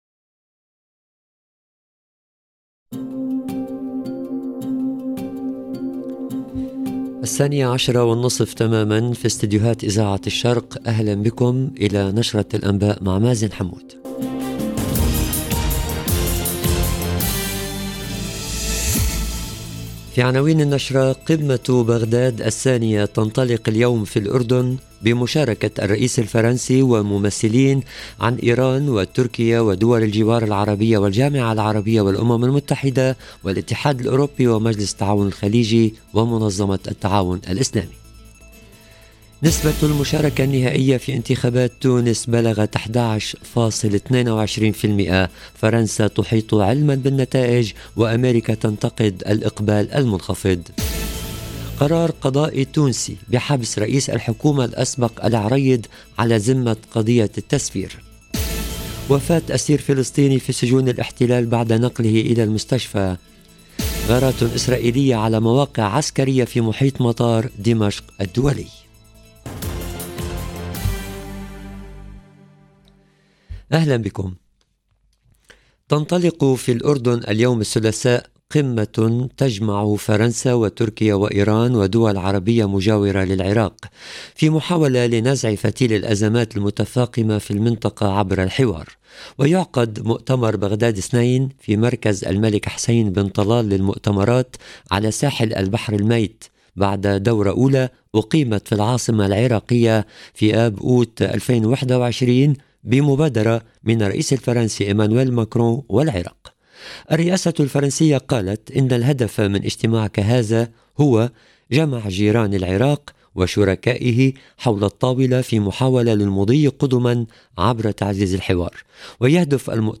LE JOURNAL EN LANGUE ARABE DE MIDI 30 DU 20/12/22